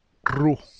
Their sound is achieved strongly “vibrating” tongue against the front of the palate: